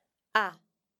We will cover both the short a and long à forms of the vowel a, with examples to get you used to the sound.